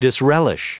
Prononciation du mot disrelish en anglais (fichier audio)
Prononciation du mot : disrelish